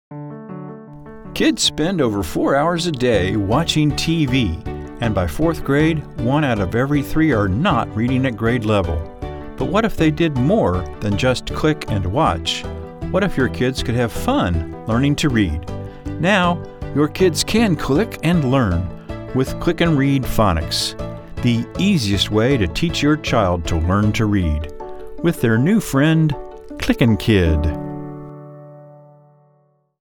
ClickNRead Phonics – Broadcast Radio Commercial